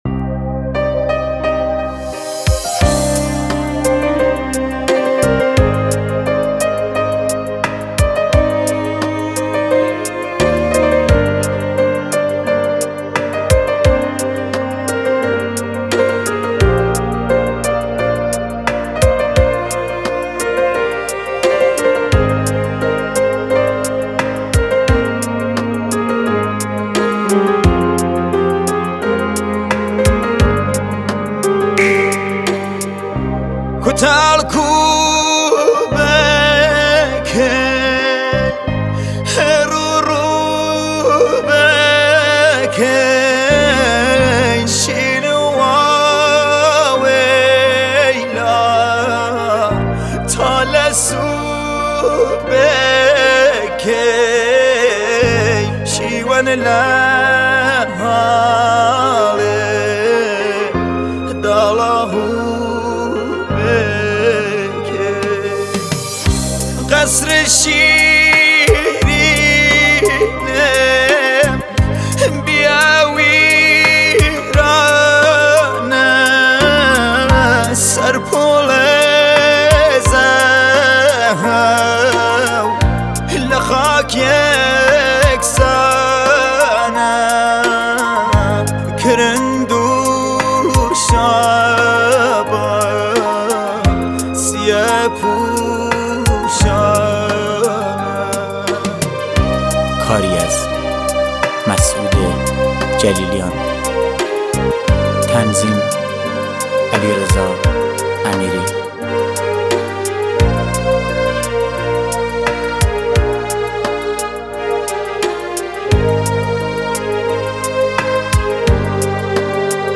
اهنگ کردی